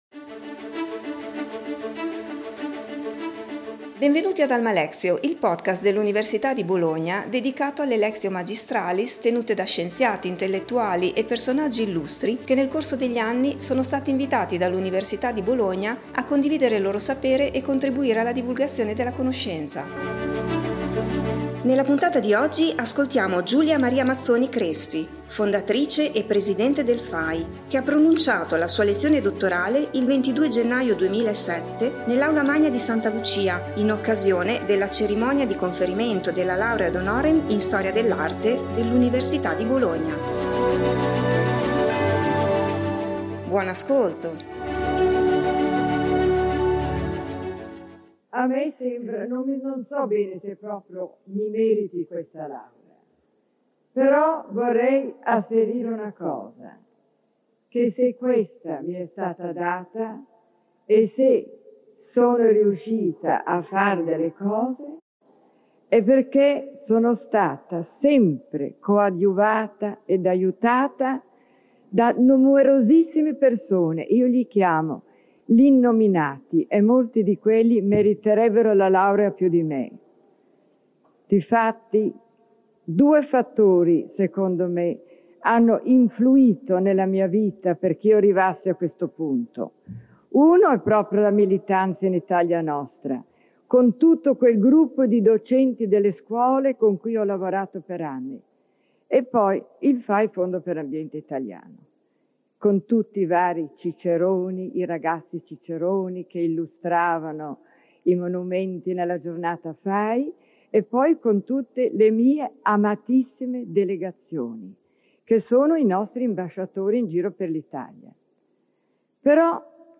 Giulia Maria Mozzoni Crespi, fondatrice e presidente del FAI (Fondo per l’Ambiente Italiano), ha pronunciato la sua lezione dottorale il 22 gennaio 2007 nell’Aula magna di Santa Lucia in occasione della cerimonia di conferimento della Laurea ad honorem in Storia dell’Arte dell'Università di Bologna.